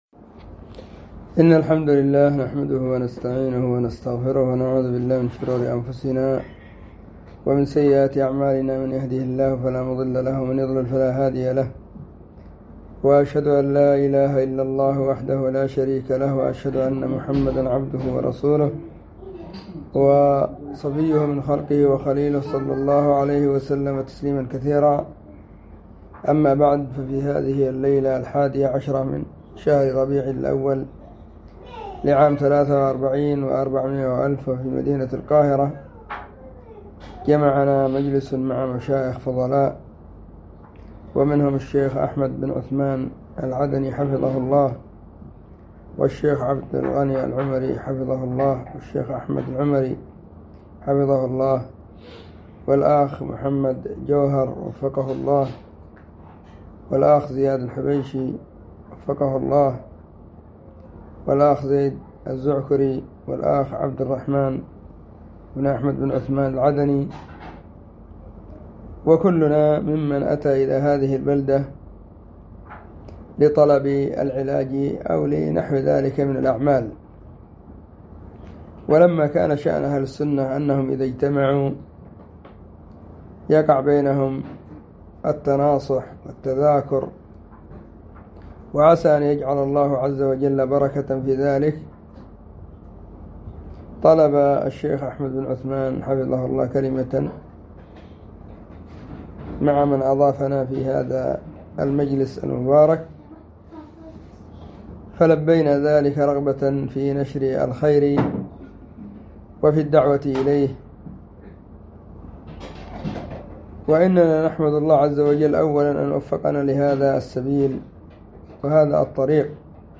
🎙كلمة بعنوان: *كلمة في القاهرة من بلاد مصر العربية*
كلمة-في-القاهرة.mp3